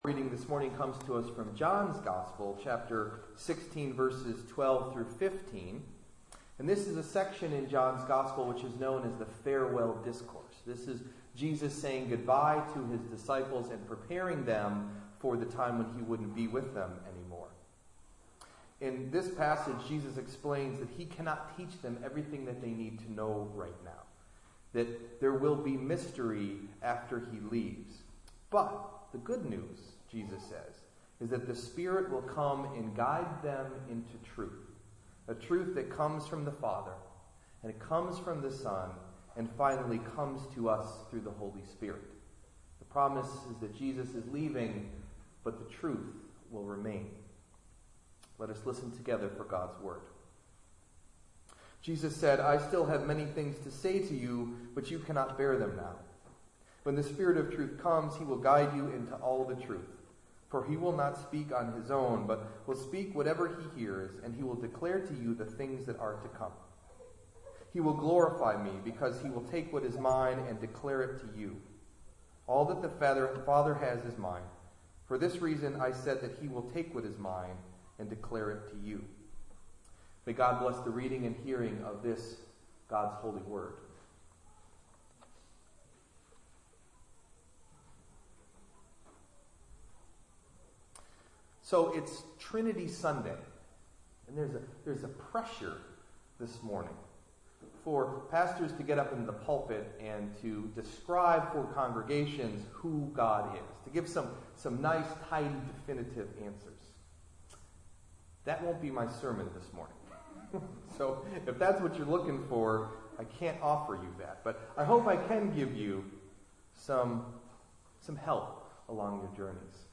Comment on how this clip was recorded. Delivered at: The United Church of Underhill